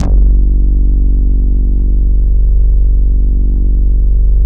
112 BASS  -R.wav